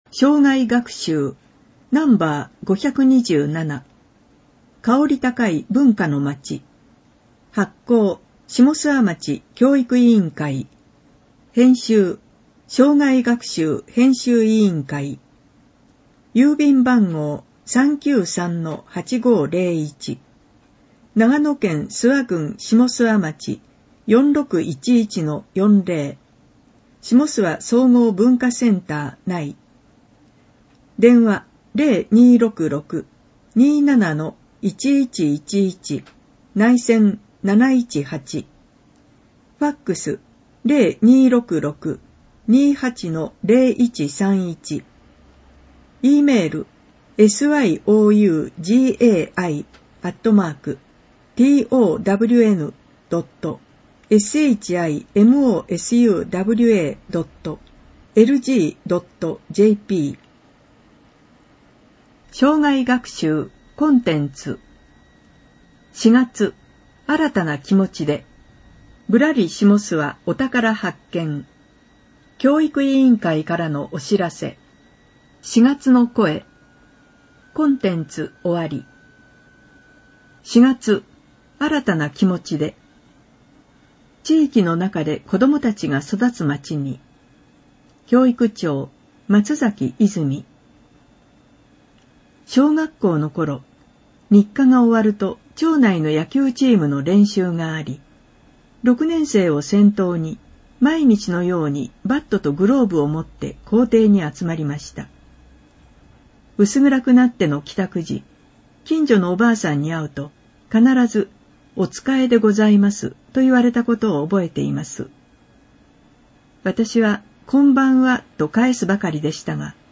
ダウンロード （音読版）クローズアップしもすわ2018年4月号 [ mp3 type：23MB ] （音読版）生涯学習５２７号 [ mp3 type：6MB ] 添付資料を見るためにはビューワソフトが必要な場合があります。